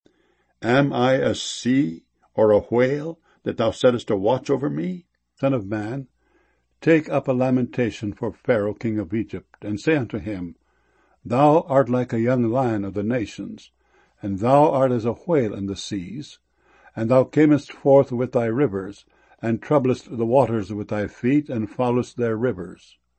whale.mp3